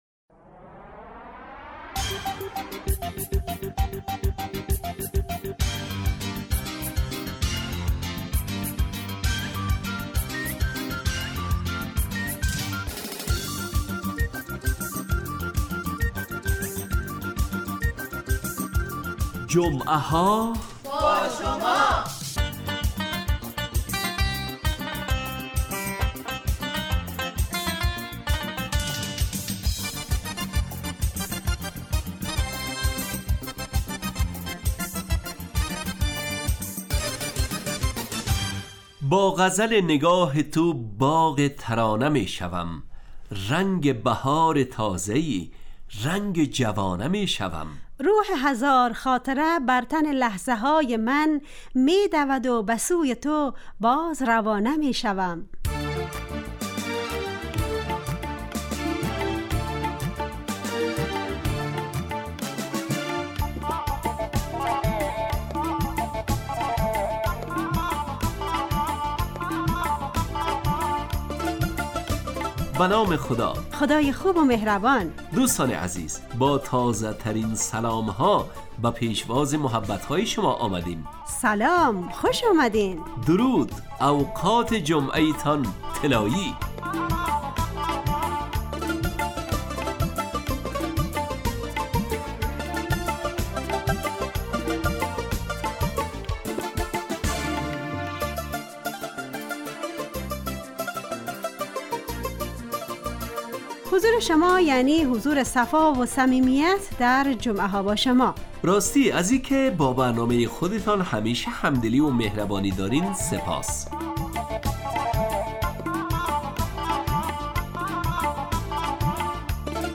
جمعه ها باشما برنامه ایست ترکیبی نمایشی که عصرهای جمعه بمدت 40 دقیقه در ساعت 18:20 دقیقه به وقت افغانستان پخش می شود و هرهفته به یکی از موضوعات اجتماعی...